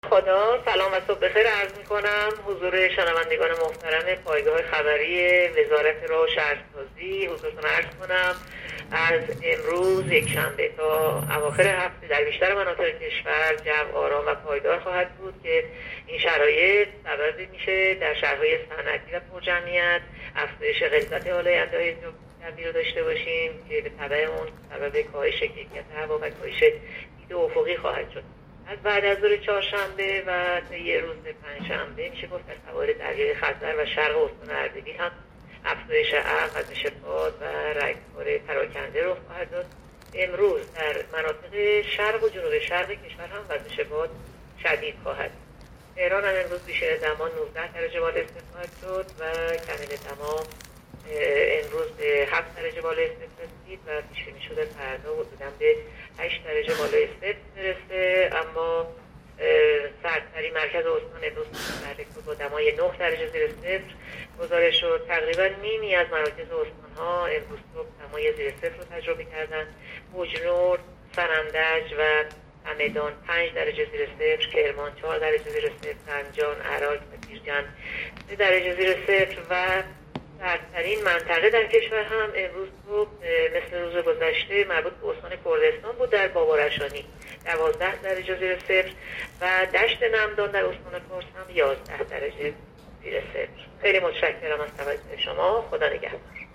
گزارش رادیو اینترنتی پایگاه‌ خبری از آخرین وضعیت آب‌وهوای دوم آذر؛